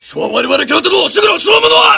mvm_sentrybuster_spin.wav